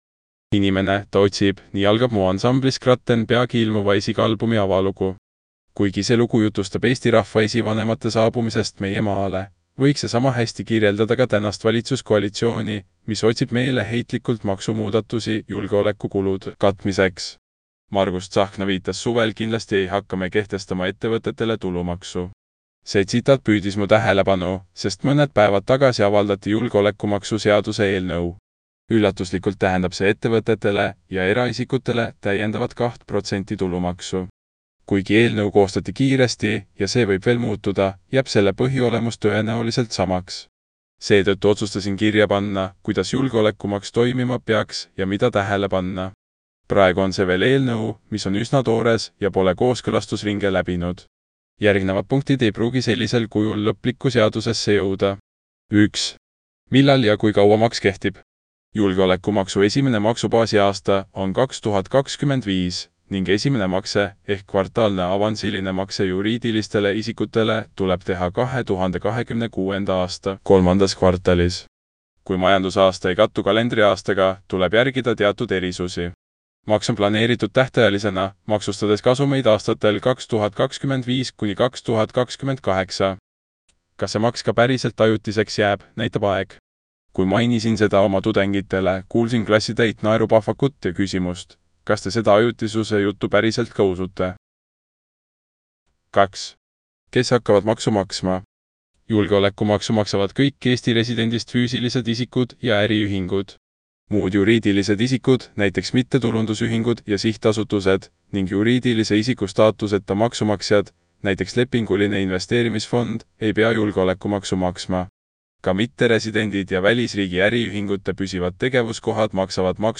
Kui silmad puhkust vajavad, anna artikkel üle kõnerobotile – vajuta ja kuula!